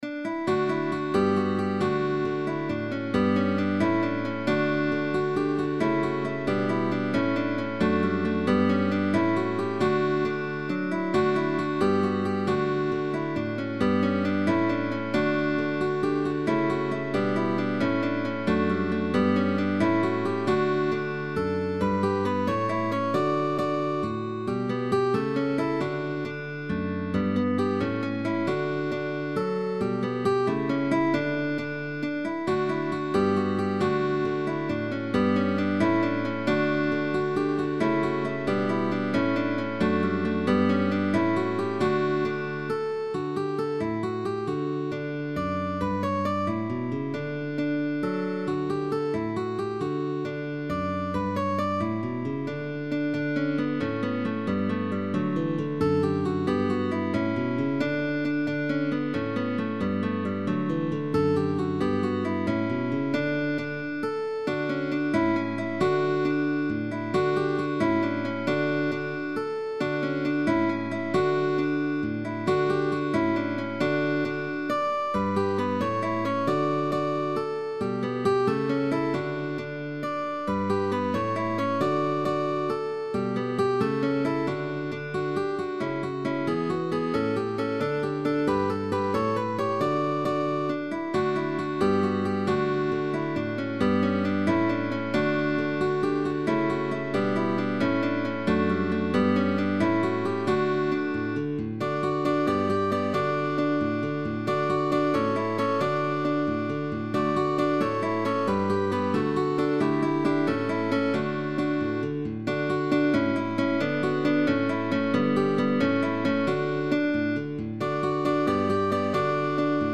guitar trio
GUITAR TRIO
Baroque